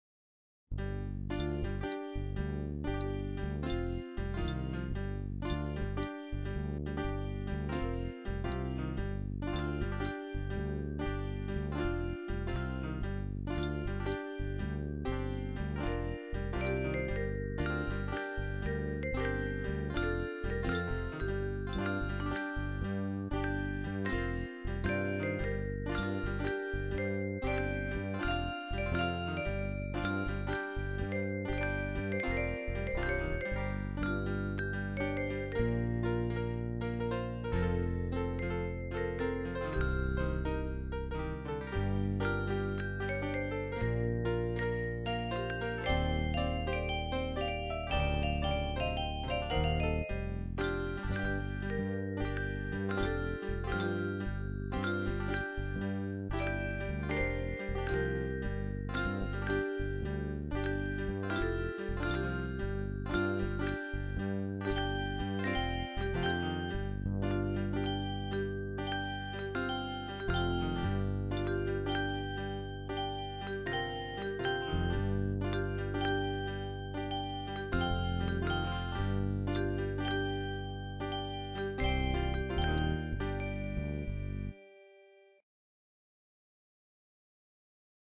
Swinging Jungle Style Tune